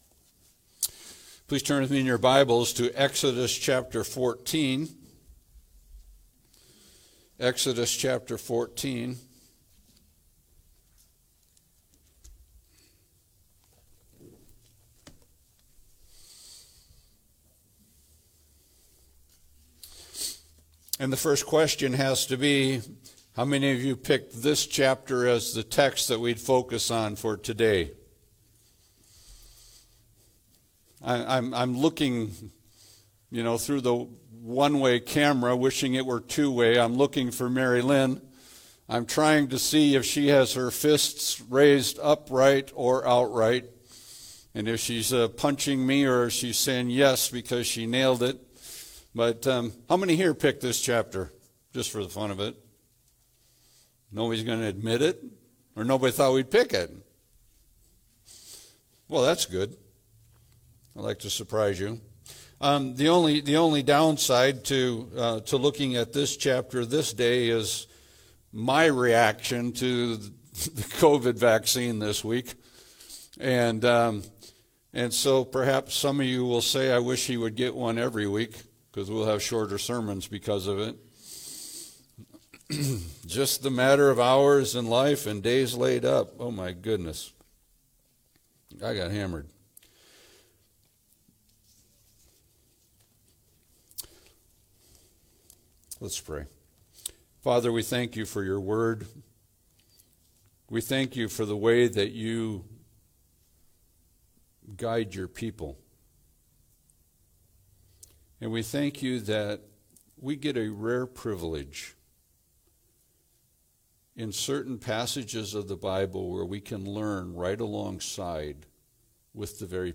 Passage: Exodus 14 Service Type: Sunday Service « Go…